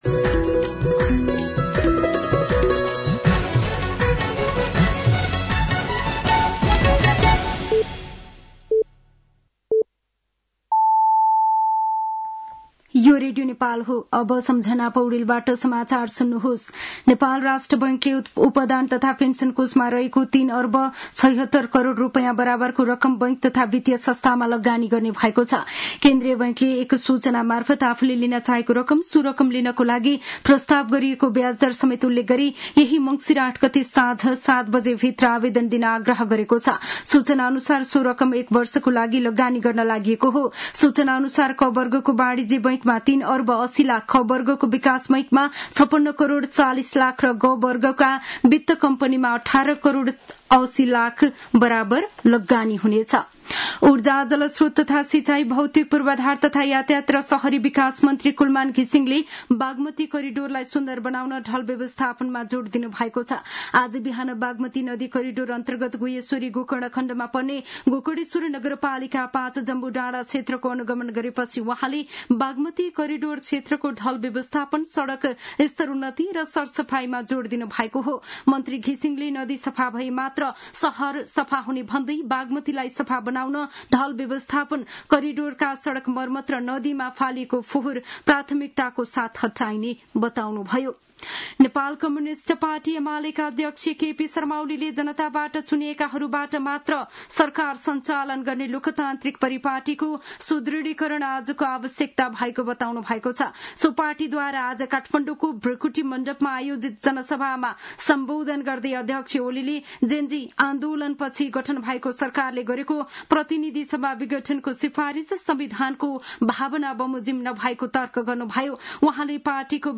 साँझ ५ बजेको नेपाली समाचार : ६ मंसिर , २०८२